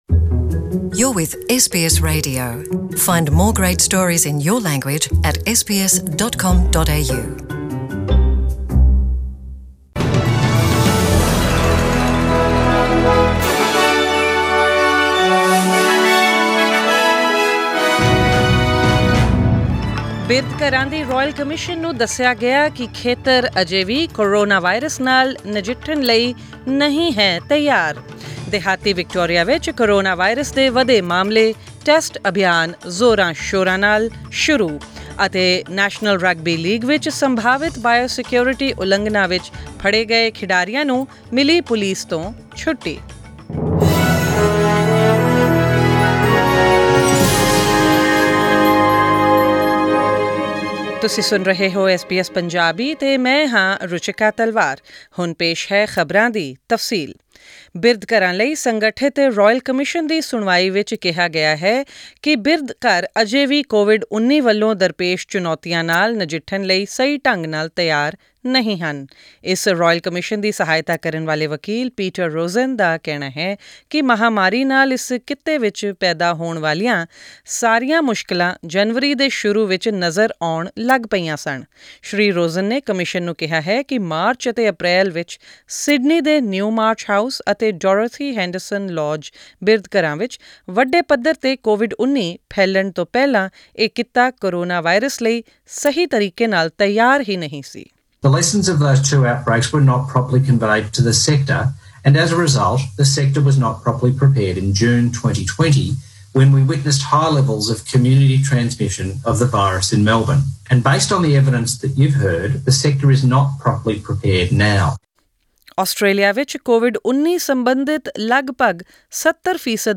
Listen to the bulletin Punjabi by clicking on the audio link in the picture above.